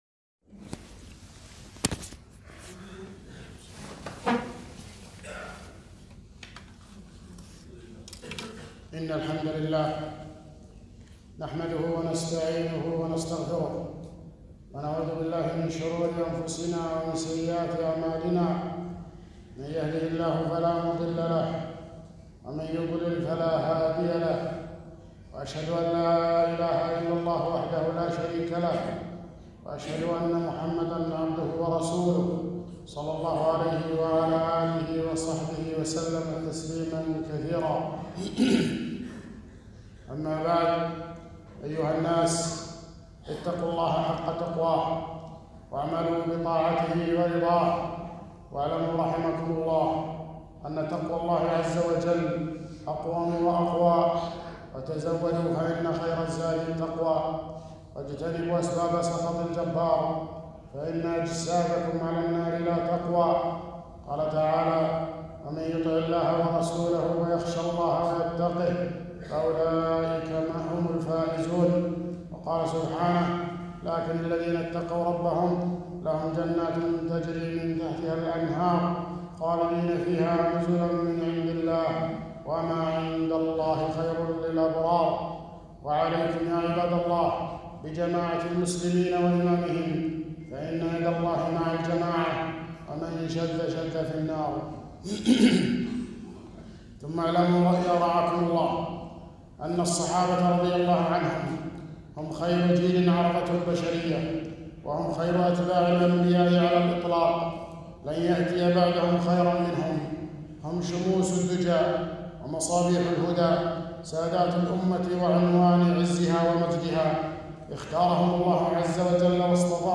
خطبة - منزلة الصحابة في الشريعة